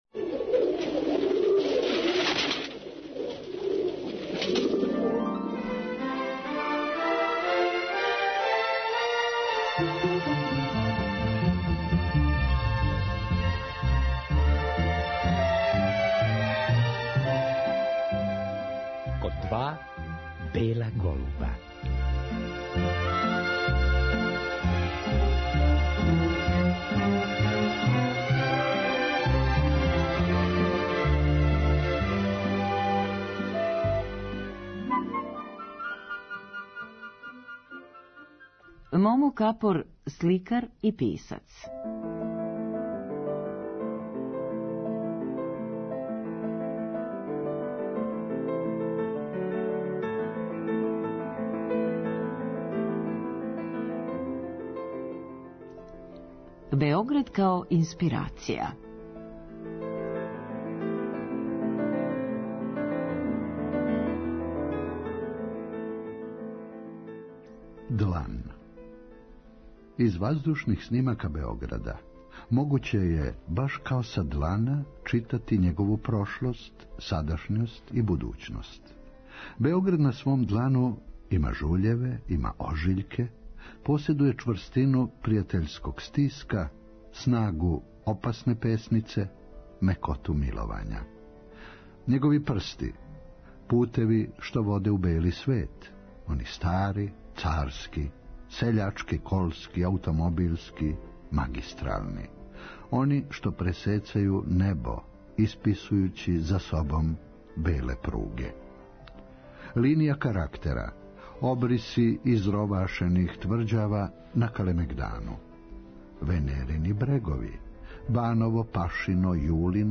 Слушаћемо емисију из 2007. године у којој је Момо говорио о свом школовању, одрастању и, наравно, о Београду.